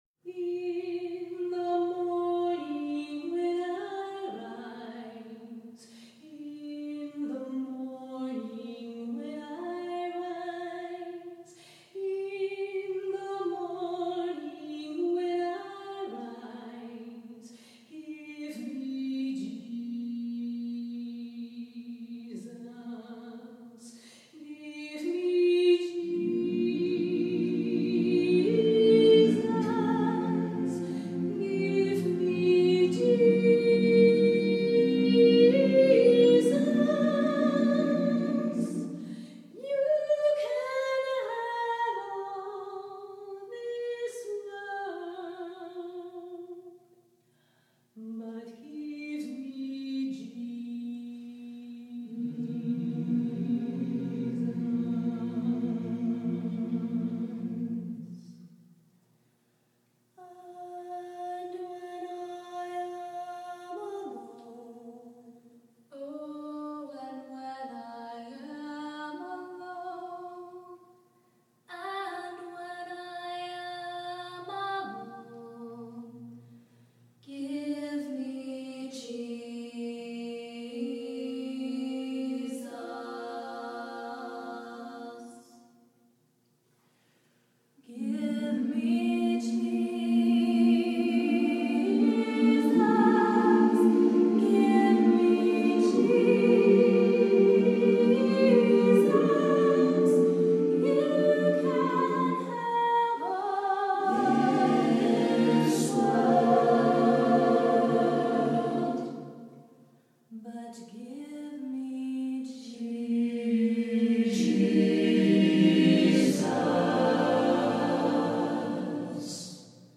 Accompaniment:      A Cappella
Music Category:      Choral
A well-known spiritual
offers a touch of jazz-blues